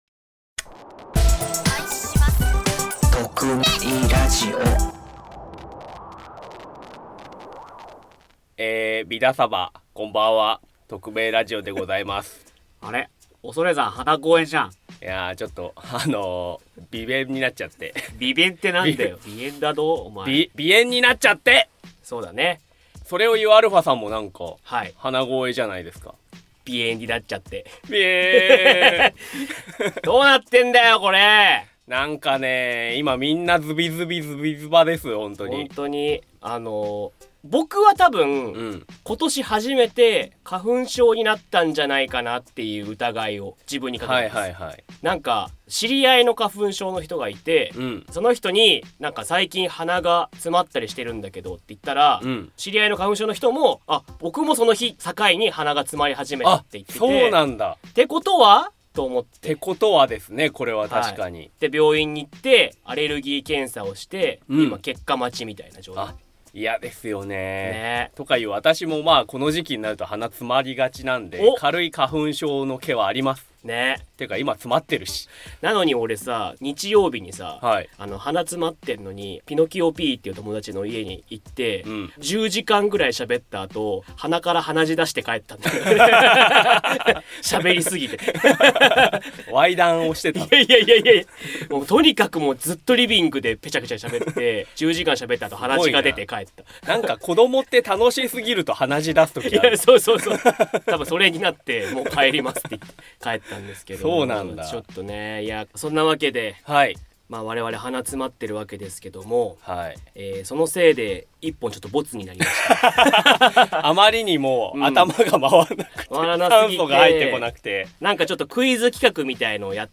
第503回は「ごめん！ダブル鼻声につき雑談をします！」です。